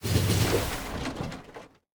train-tie-3.ogg